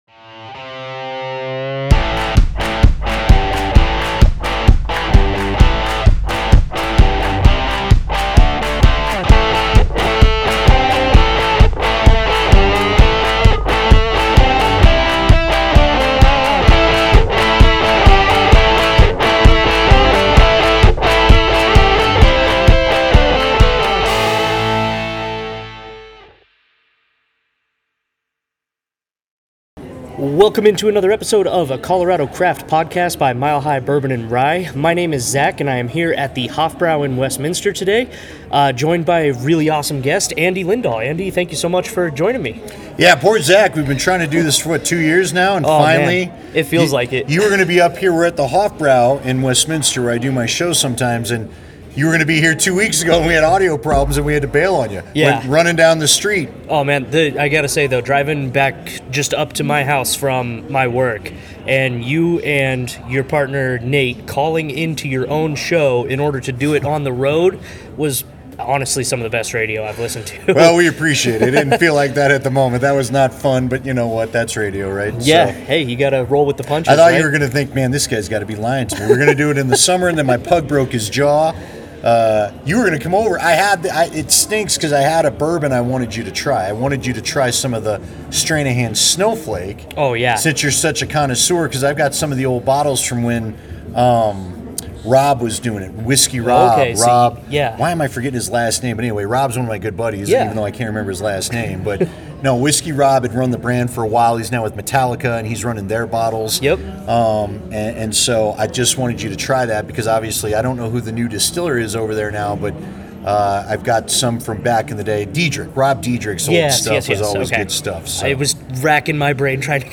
This was an awesome conversation, thank […]